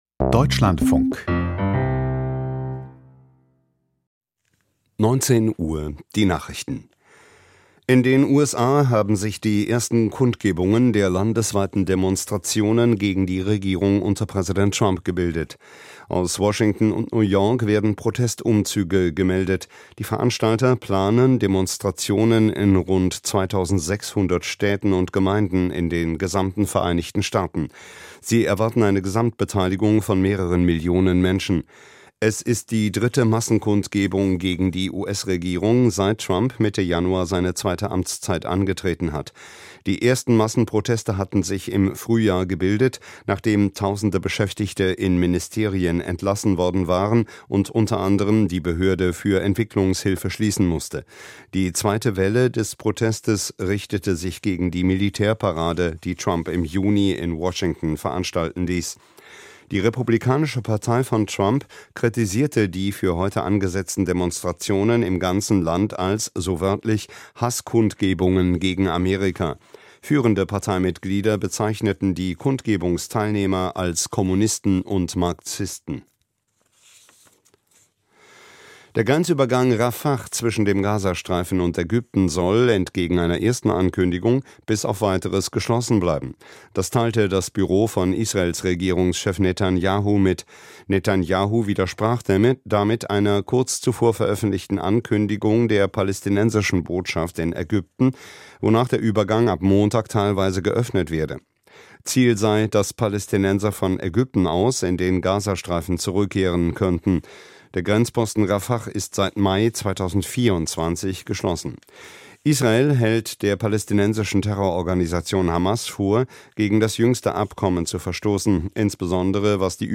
Die Nachrichten